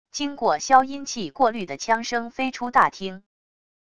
经过消音器过滤的枪声飞出大厅wav音频